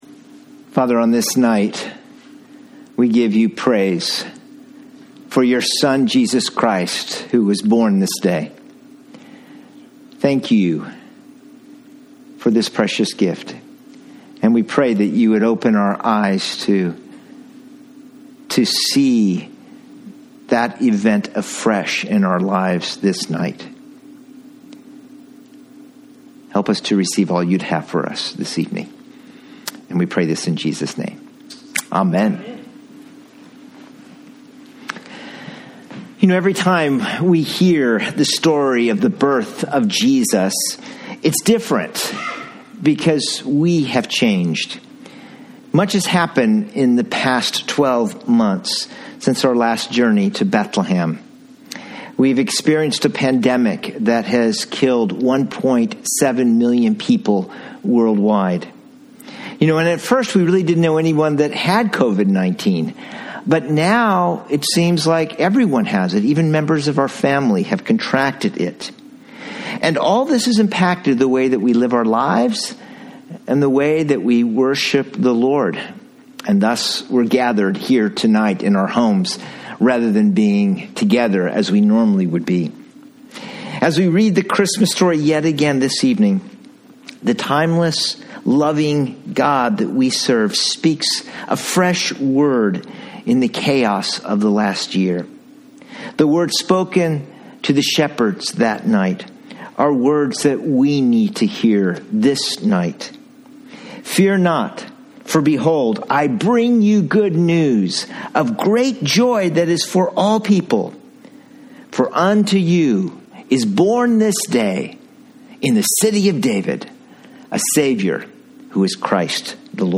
Titus 2:11-14 Christmas Eve Service